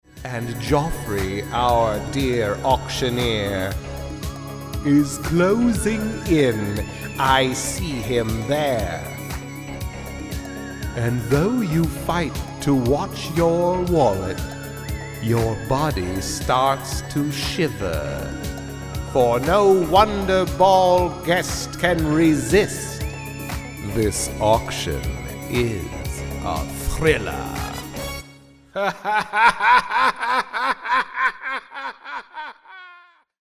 Last year, for a local event, I produced a bit of Vincent Price style VO, which was ultimately produced/mixed with a Thrilleresque background theme.
Tags: Halloween voice overs, scary voices, vincent price soundalike, voice actor spooky